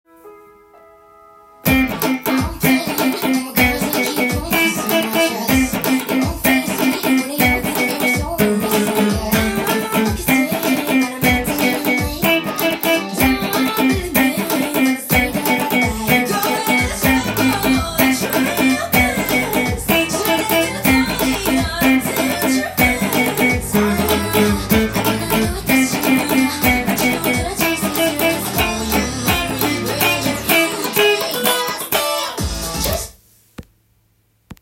音源に合わせて譜面通り弾いてみました
情熱的なコード進行が特徴的な
１６分音符が出てきたらオルタネイトピッキングで
カッティング奏法をしながら弾いていきます。